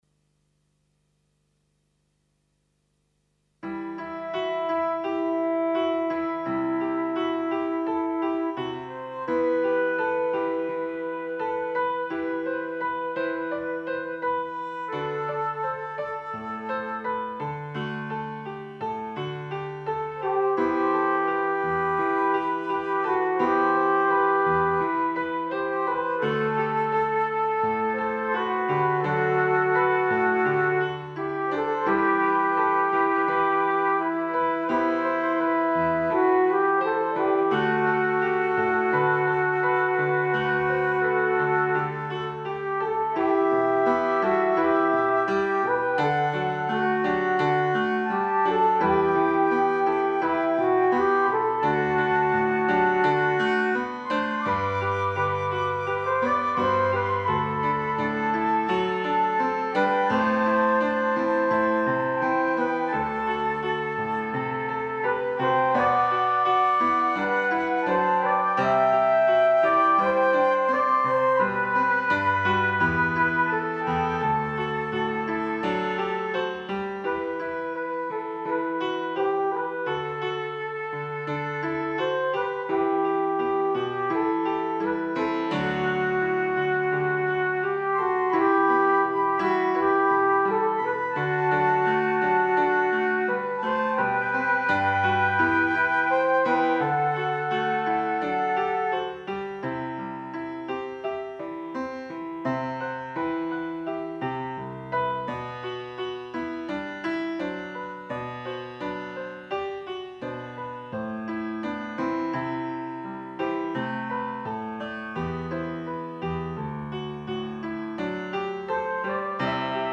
Solo voice and Flute with Keyboard accompaniment
A beautiful love song with lyrics which can be read
Here arranged for Solo Voice and Flute
with piano accompaniment